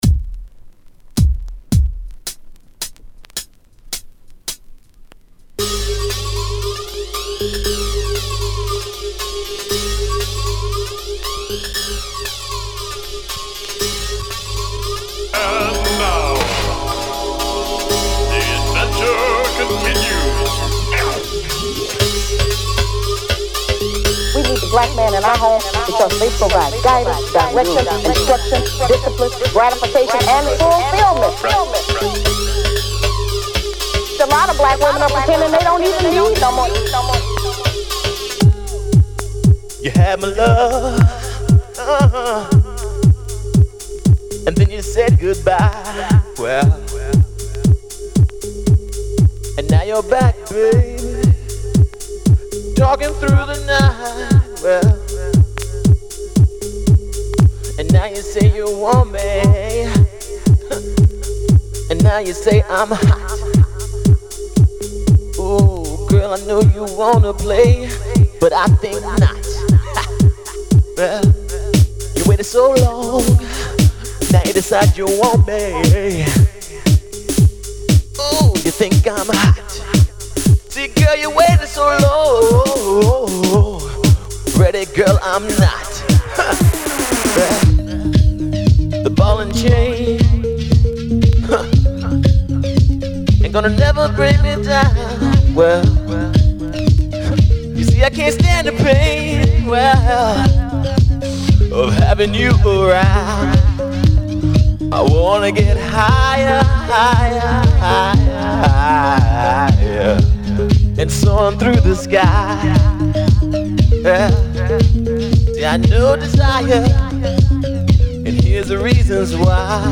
Gasp at the insipidness of the beat!